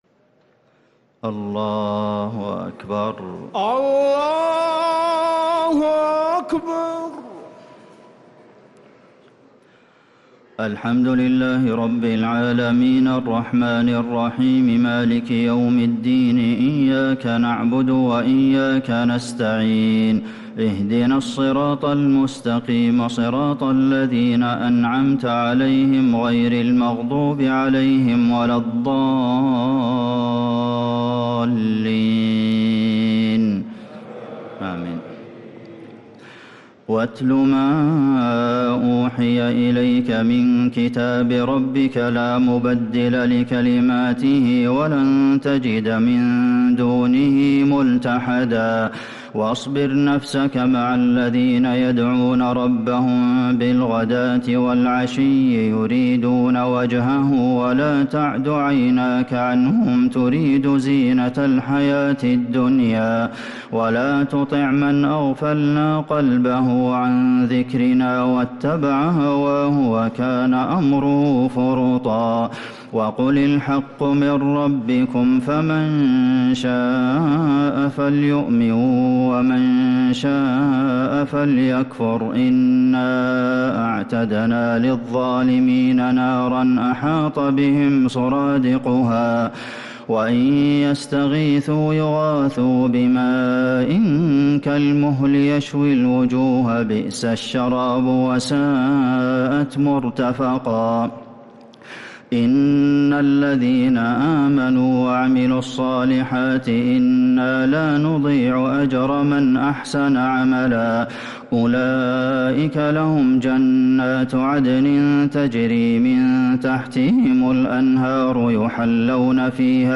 تراويح ليلة 20 رمضان 1446هـ من سورة الكهف (27-82) | Taraweeh 20th night Ramadan1446H Surah Al-Kahf > تراويح الحرم النبوي عام 1446 🕌 > التراويح - تلاوات الحرمين